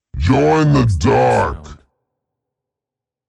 “Join the dark” Clamor Sound Effect
Can also be used as a car sound and works as a Tesla LockChime sound for the Boombox.